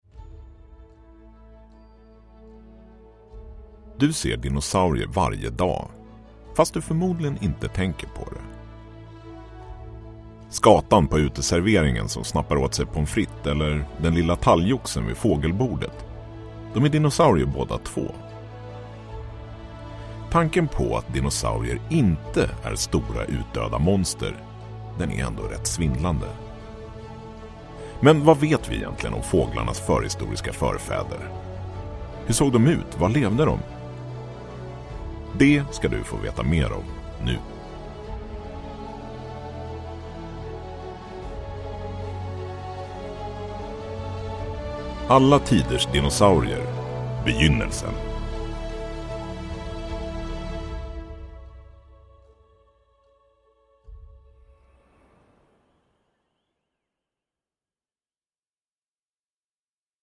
Alla tiders dinosuarier 1 - I begynnelsen – Ljudbok – Laddas ner